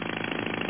Amiga 8-bit Sampled Voice
drill2m.mp3